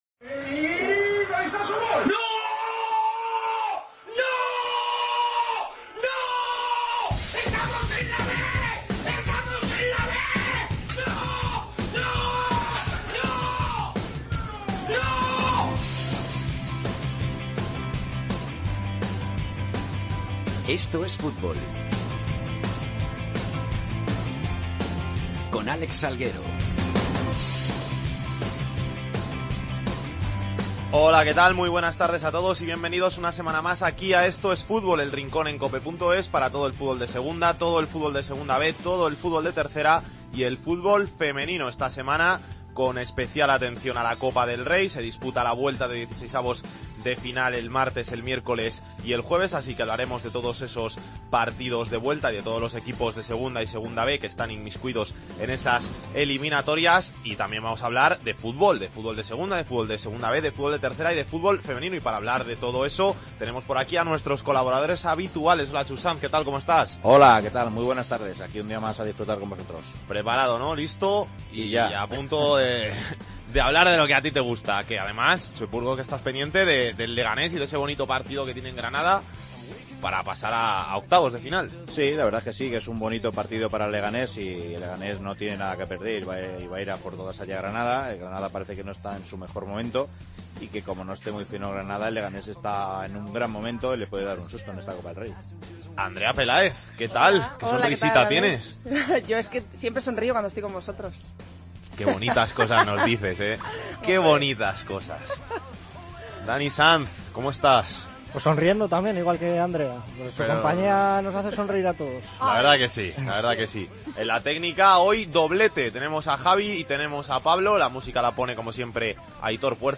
Entrevista a Charlyn Corral, delantera mexicana del Levante.